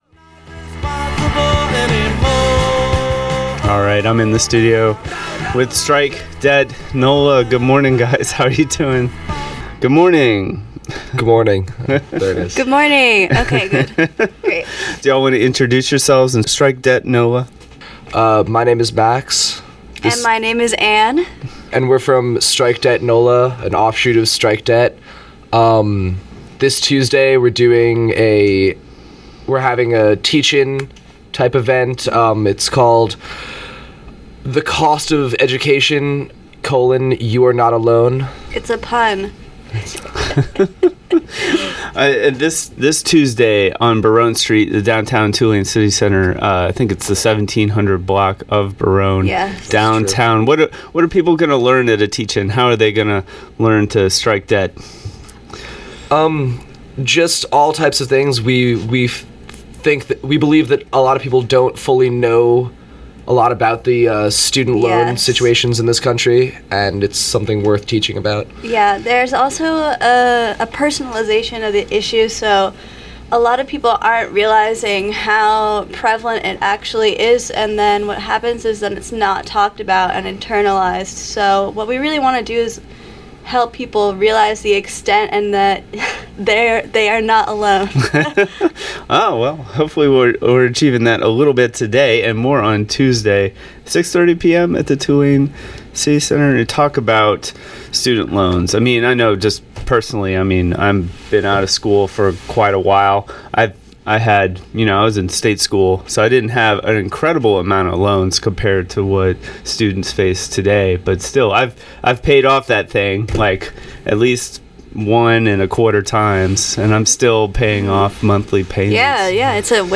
WTUL news and views interviews strike debt nola